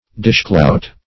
Dishclout \Dish"clout`\, n.